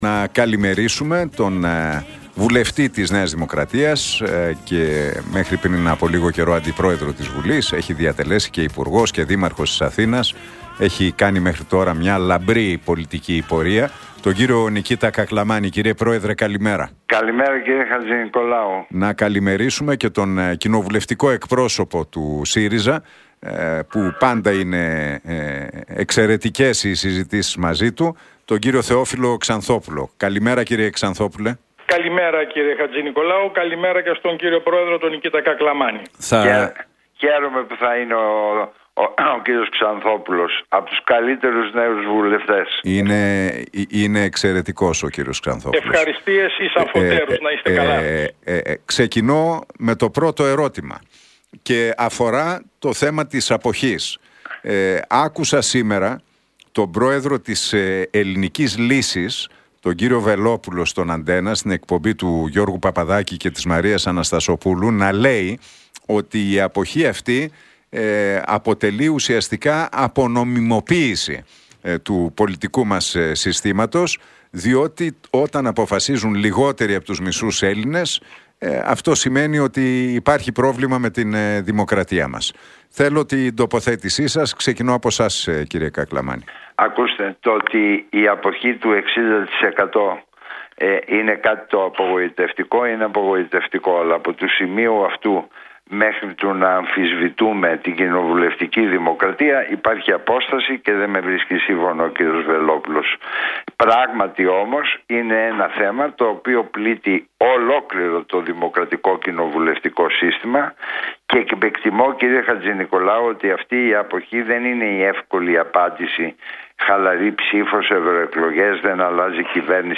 Debate Κακλαμάνη - Ξανθόπουλου στον Realfm 97,8 για την αποχή στις Ευρωεκλογές και τον ανασχηματισμό
Τα «ξίφη τους διασταύρωσαν» στον αέρα του Realfm 97,8 και την εκπομπή του Νίκου Χατζηνικολάου ο βουλευτής της ΝΔ, Νικήτας Κακλαμάνης και ο Κοινοβουλευτικός Εκπρόσωπος του ΣΥΡΙΖΑ, Θεόφιλος Ξανθόπουλος, στον απόηχο των Ευρωεκλογών.